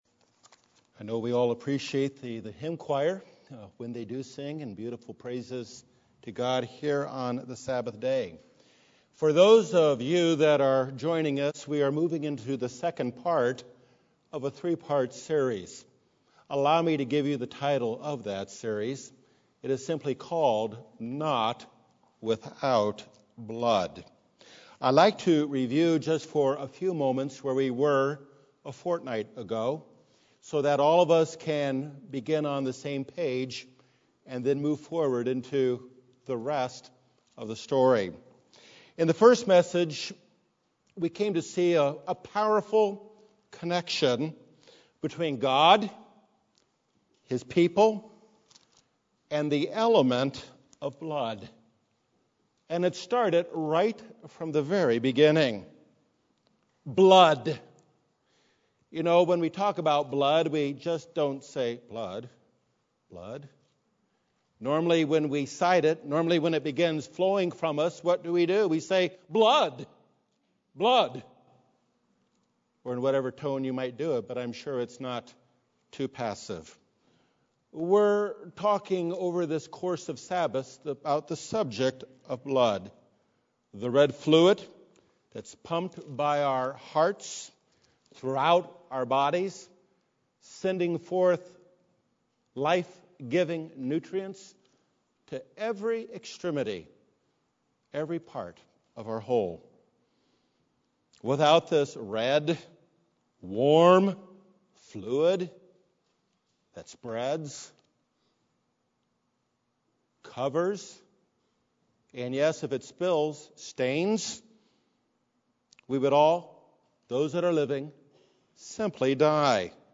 Part 2 of 3 in a series explaining that to have a relationship with God is "Not Without Blood" (Hebrews 9:7). This sermon provides Christ's commentary on the necessity of blood in a covenantal relationship.